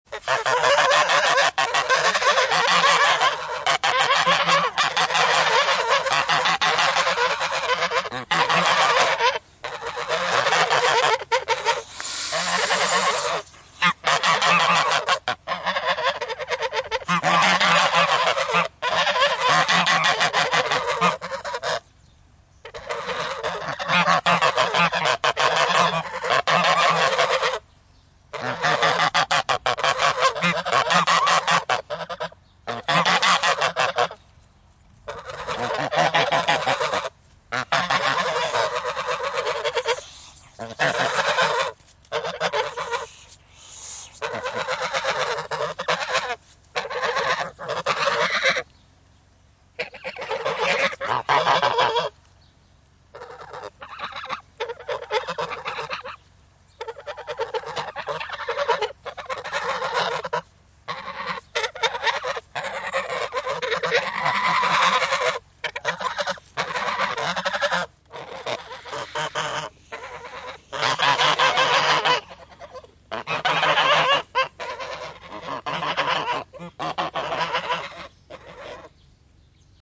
Geese Multiple Angry Shouting Hissing Close Sound Effect Download: Instant Soundboard Button